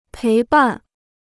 陪伴 (péi bàn) Dictionnaire chinois gratuit